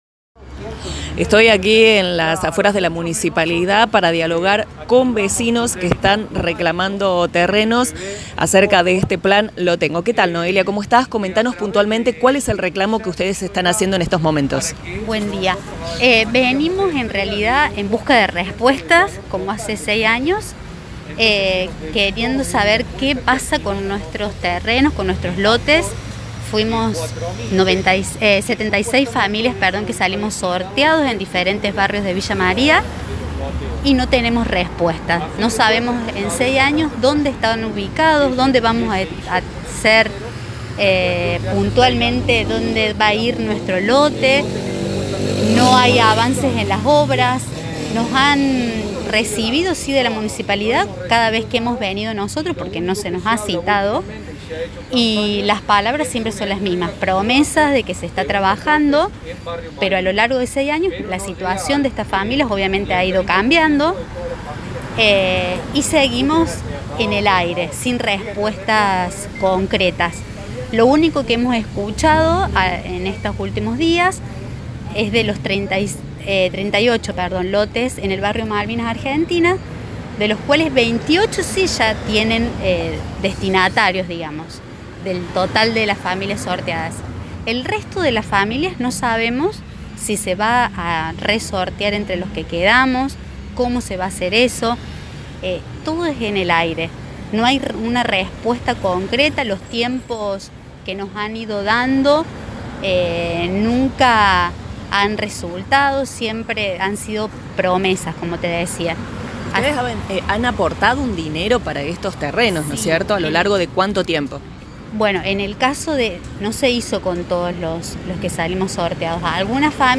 Escuche la palabra de quienes estaban encabezando la protesta este martes: